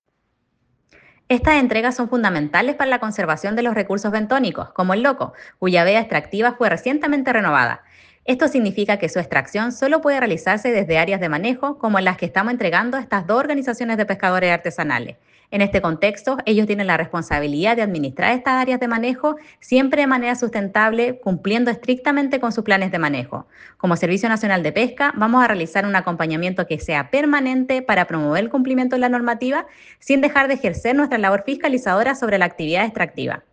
Daniela Leiva Directora Regional de Sernapesca en Aysén